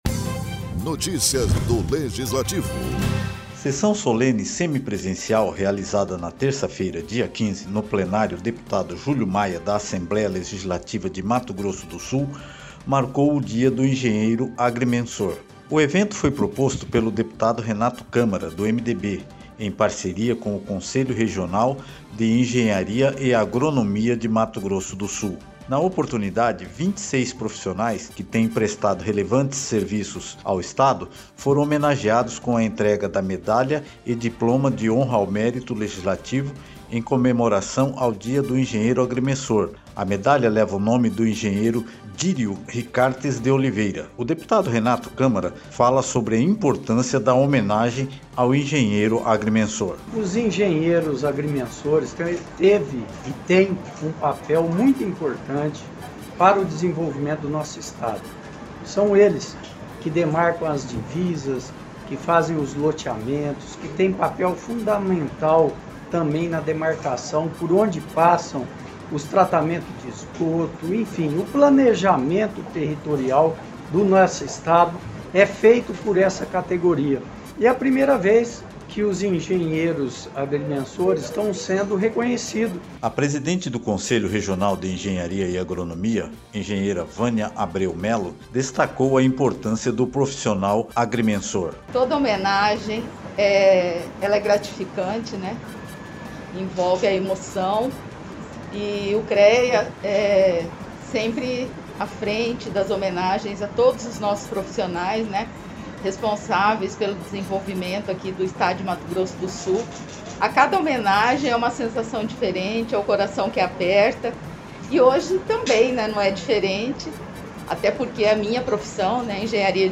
Com a realização de uma Sessão Solene Semipresencial a Assembleia Legislativa de Mato Grosso do Sul (ALEMS) comemorou o Dia do Engenheiro Agrimensor.
Produção e Locução